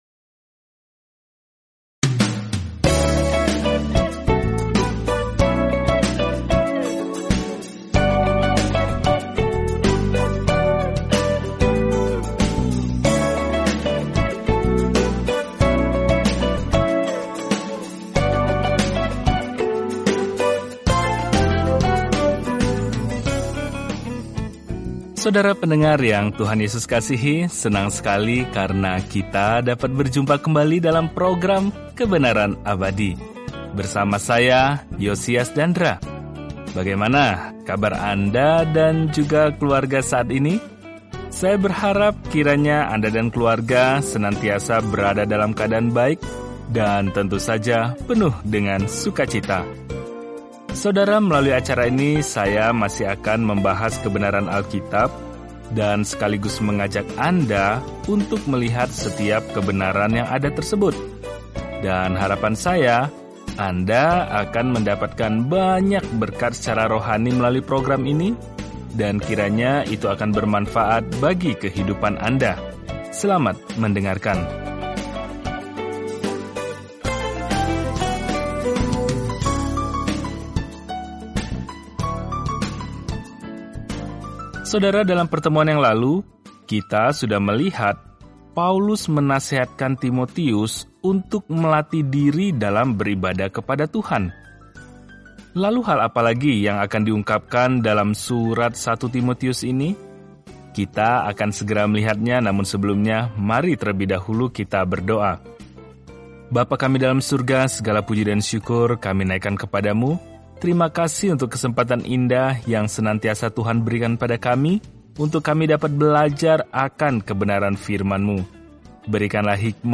Firman Tuhan, Alkitab 1 Timotius 4:8-16 Hari 9 Mulai Rencana ini Hari 11 Tentang Rencana ini Surat pertama kepada Timotius memberikan indikasi praktis bahwa seseorang telah diubah oleh Injil – tanda-tanda kesalehan yang sejati. Telusuri 1 Timotius setiap hari sambil mendengarkan pelajaran audio dan membaca ayat-ayat tertentu dari firman Tuhan.